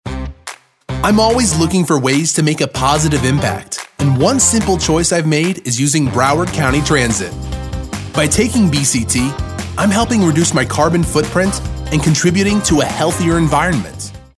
Great young adult voice and raspy sound!
animated, anti-announcer, confident, conversational, cool, genuine, guy-next-door, hipster, millennial, real, upbeat, young adult